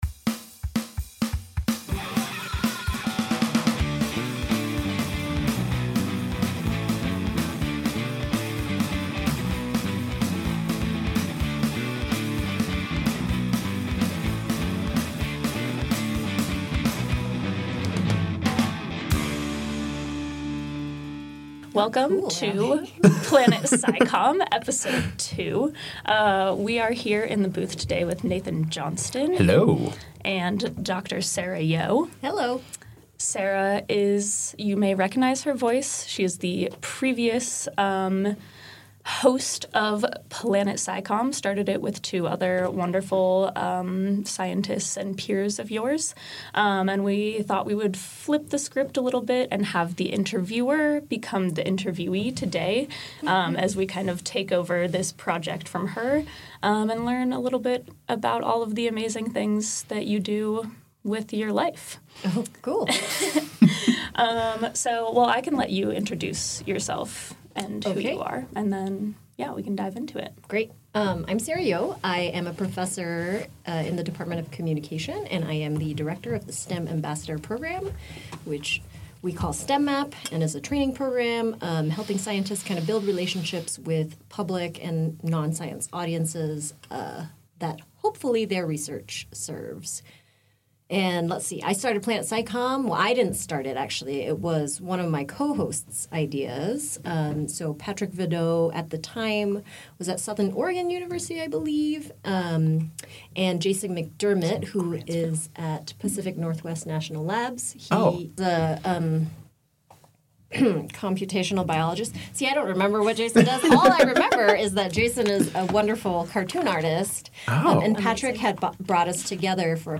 Welcome to the Planet SciComm podcast, where a science communication enthusiast, practitioner, and researcher talk about science communication and any other musings that come to mind!